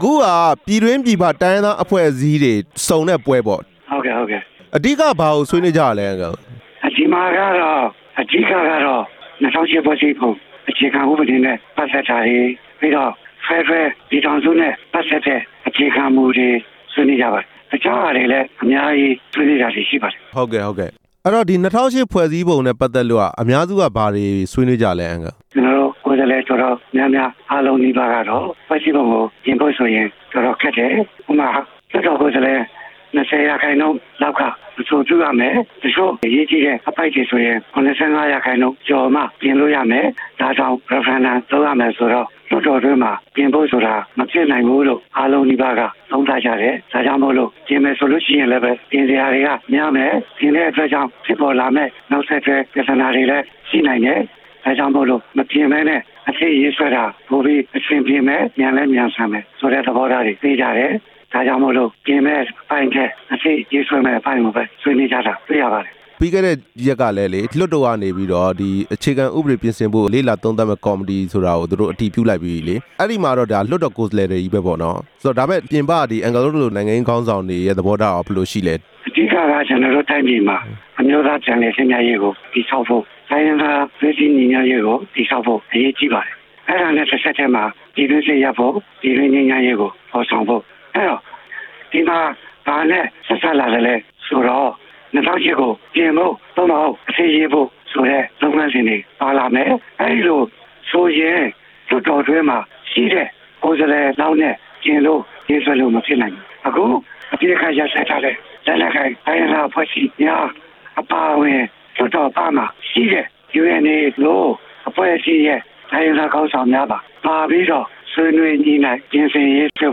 ချင်းမိုင်မြို့က တိုင်းရင်းသားပေါင်းစုံ ဆွေးနွေးပွဲအကြောင်း ဦးအေးသာအောင်နဲ့ ဆက်သွယ်မေးမြန်းချက်